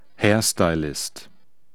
Aussprache:
🔉[ˈhɛːɐ̯staɪ̯lɪst]